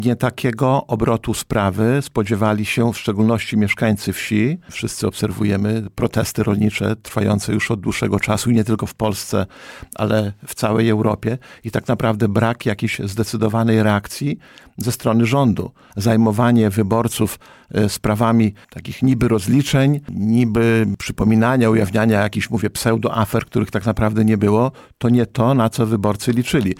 Starosta zwrócił również uwagę na to, że wyborcy przy urnach pokazali swoje niezadowolenie z działań obecnego rządu.